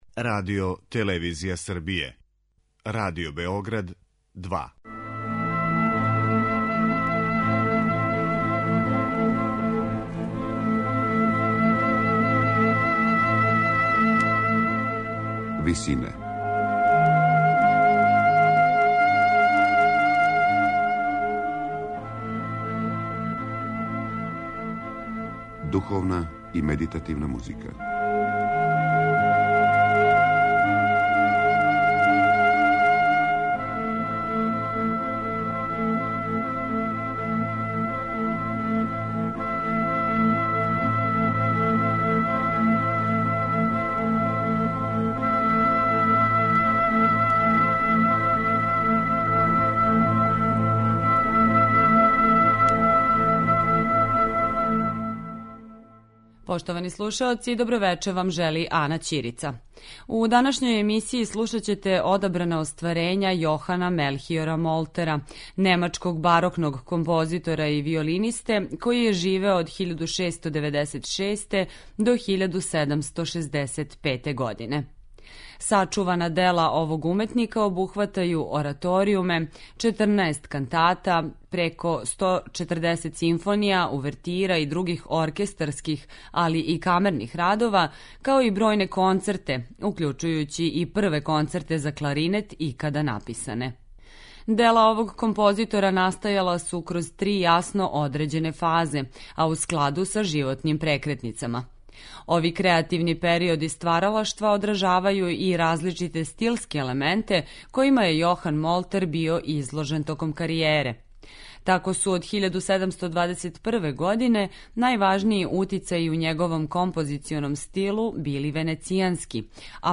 Духовна и медитативна музика